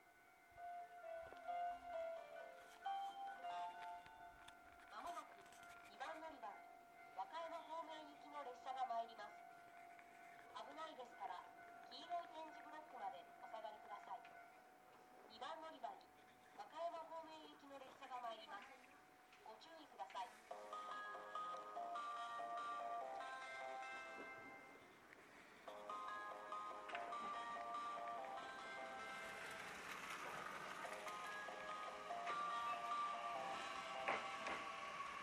２番線T：和歌山線
接近放送普通　和歌山行き接近放送です。